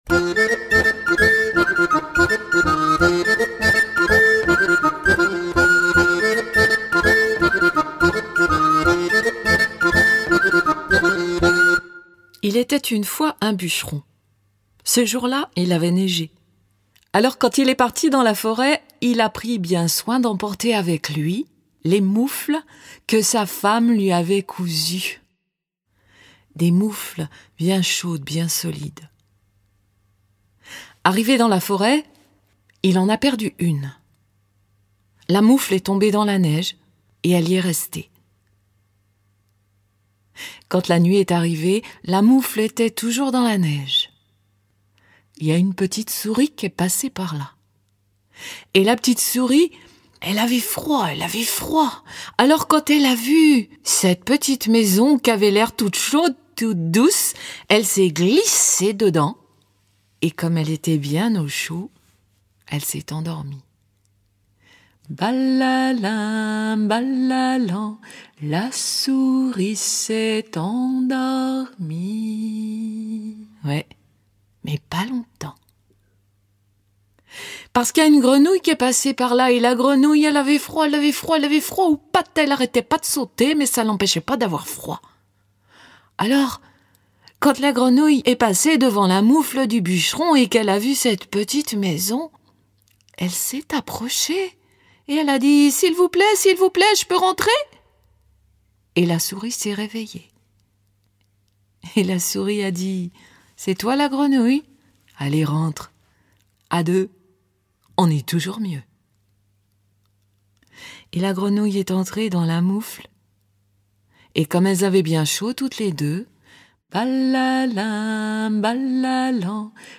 Diffusion distribution ebook et livre audio - Catalogue livres numériques
De la légende de Saint-Nicolas à la Befana, de l’histoire de la Moufle à celle de La petite fille de neige, autant d’histoires où conteuses et conteur prennent tour à tour la parole pour une grande glissade dans l’hiver et le froid…